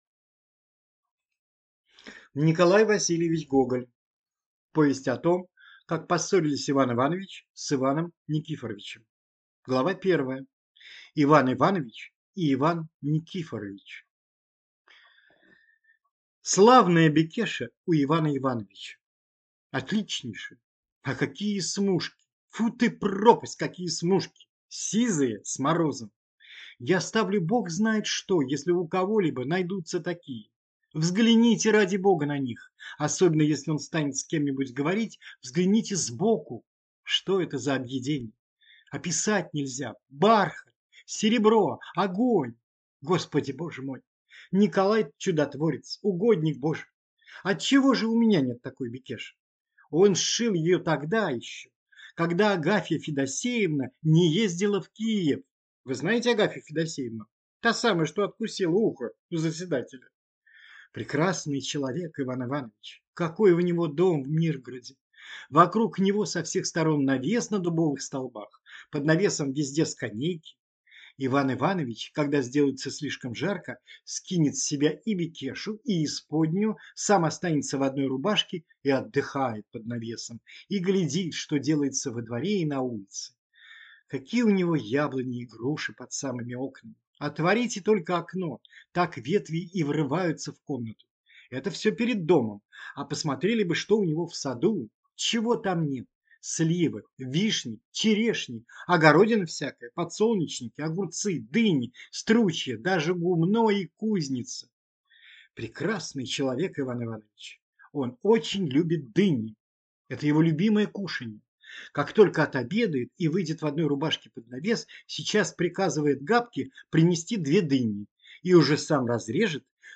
Аудиокнига Повесть о том, как поссорился Иван Иванович с Иваном Никифоровичем | Библиотека аудиокниг